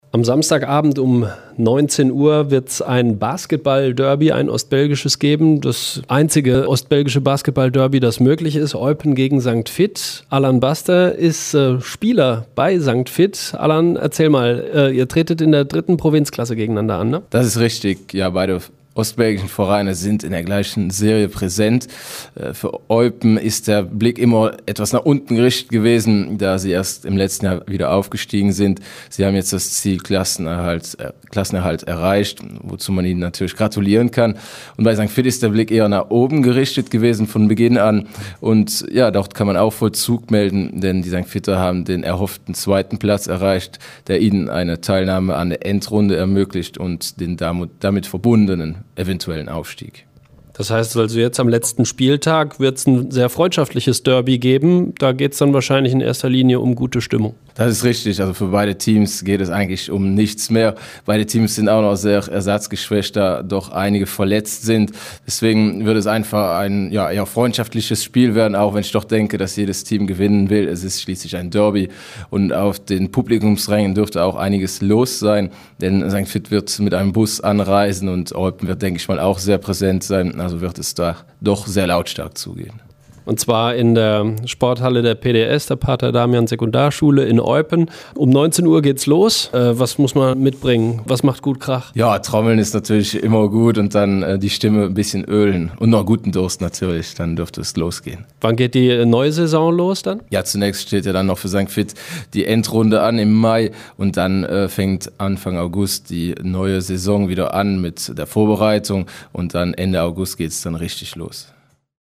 über die Partie unterhalten: